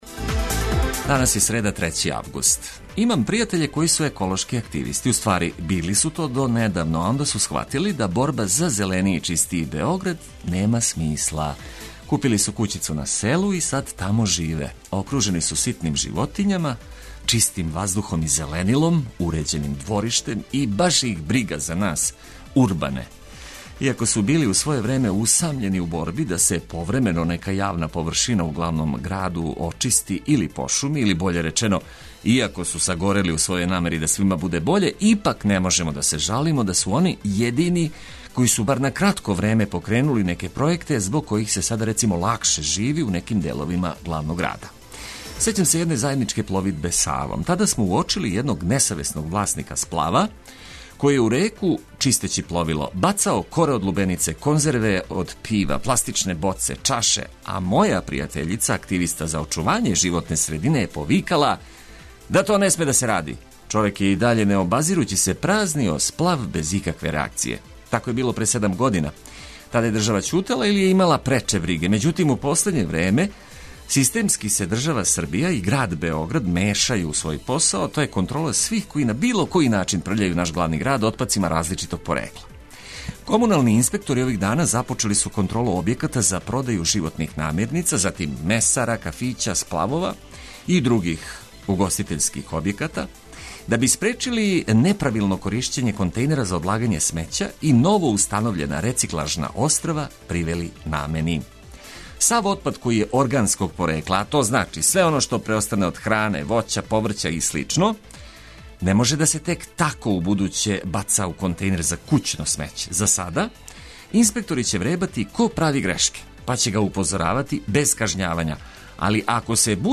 Ведро буђење уз приче од којих сви имају користи, и музика за дизање из кревета током целог јутра.